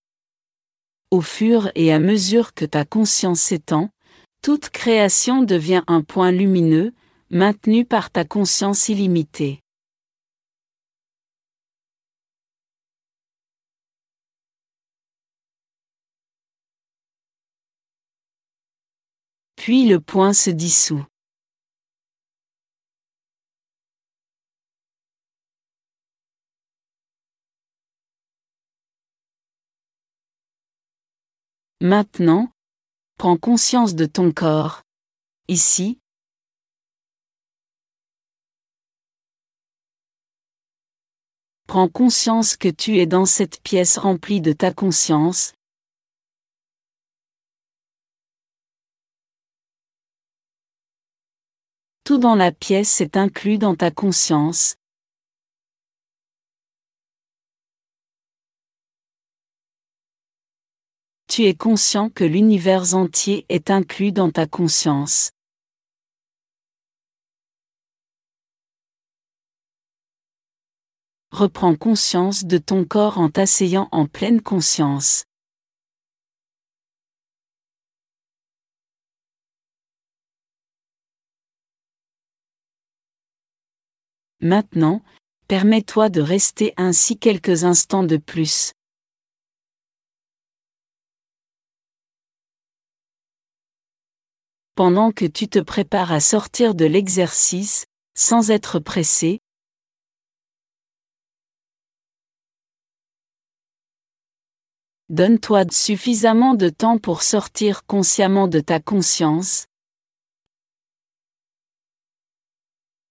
Méditation de la Présence Consciente